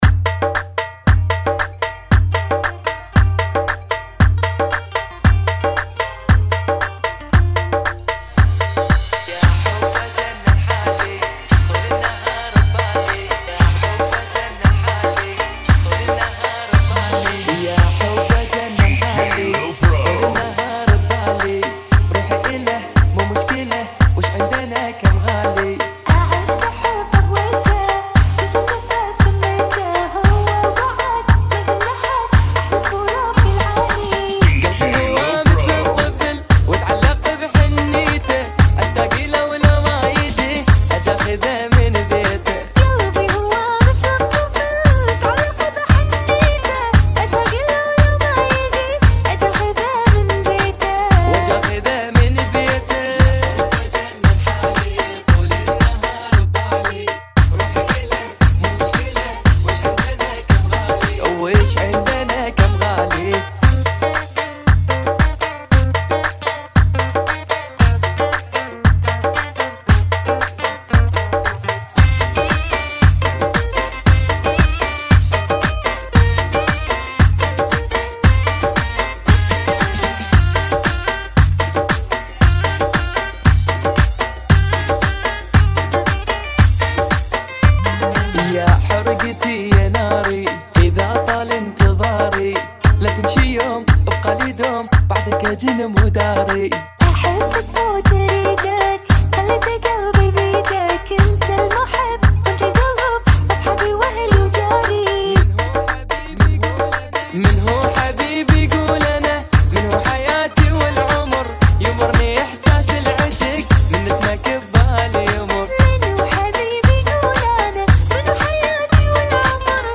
115 Bpm ] - Funky